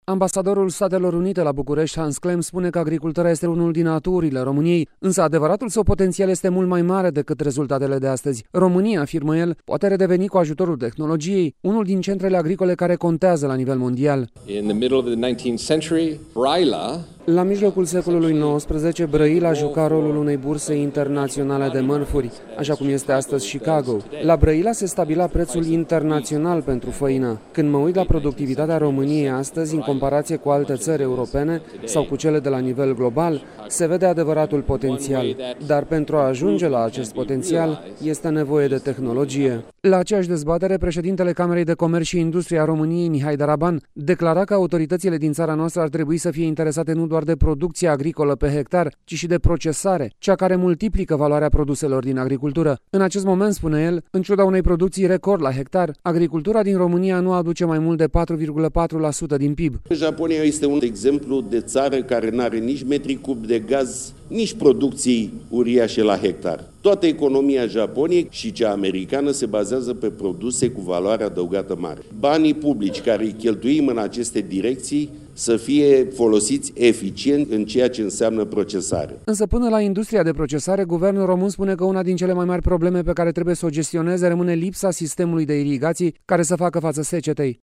Produsele româneşti autentice trebuie sprijinite pentru a fi cunoscute pe plan internaţional, spune premierul Viorica Dăncilă. Prezentă ieri la Târgul internaţional de produse agricole INDAGRA, ea a precizat că trebuie găsite soluţii concrete pentru susţinerea producătorilor din ţara noastră.